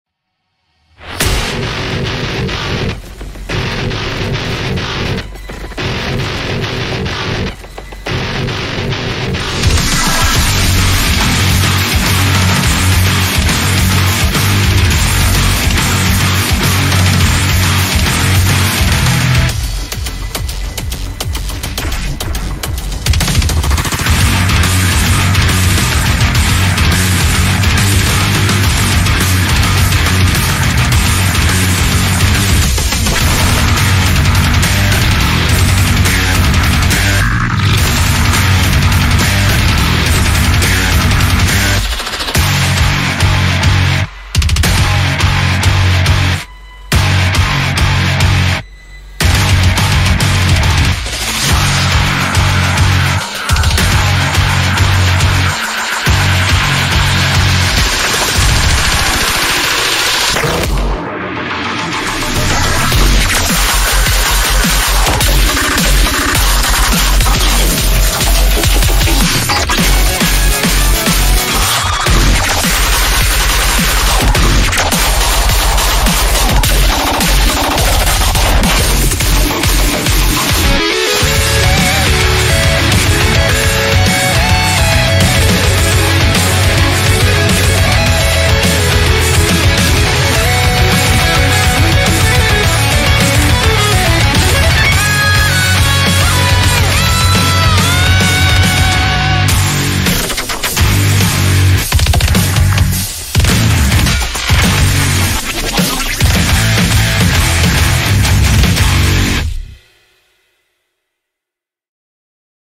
BPM53-420
Audio QualityPerfect (Low Quality)